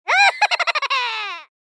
Комичный смех